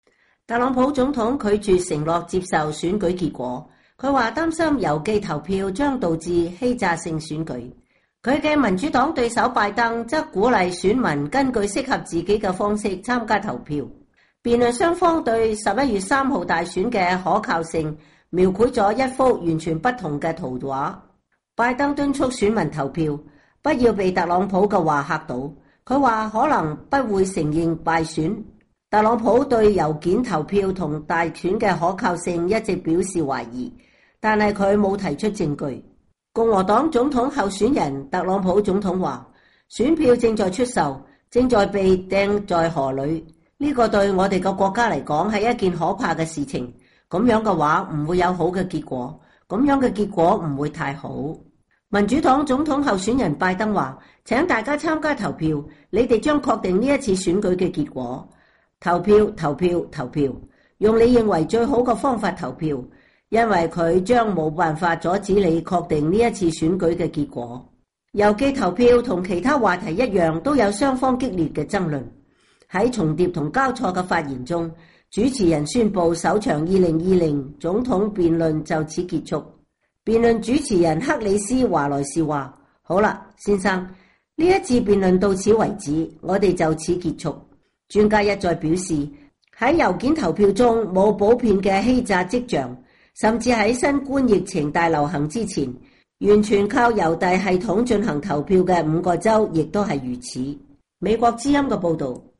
特朗普和拜登激烈爭辯。
郵寄投票和其他話題一樣都有雙方激烈的爭論。在重疊和交錯的發言中，主持人宣布首場2020總統辯論就此結束。